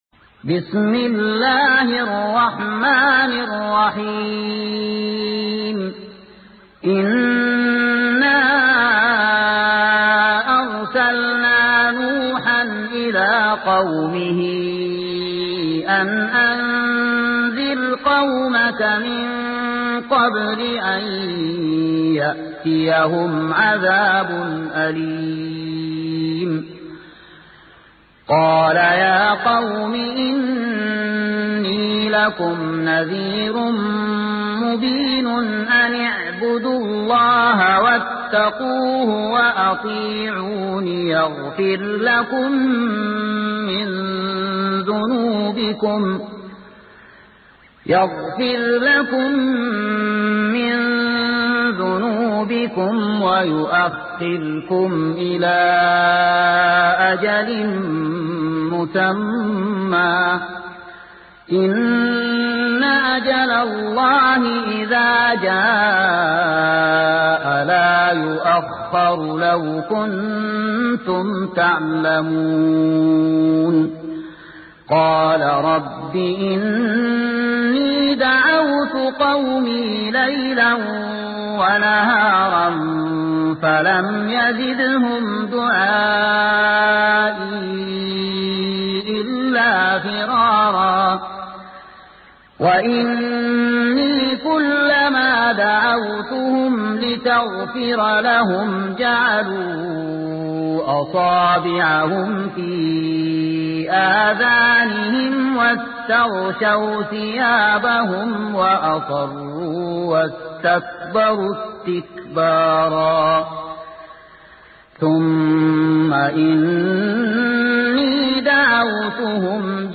سورة نوح مكية عدد الآيات:28 مكتوبة بخط عثماني كبير واضح من المصحف الشريف مع التفسير والتلاوة بصوت مشاهير القراء من موقع القرآن الكريم إسلام أون لاين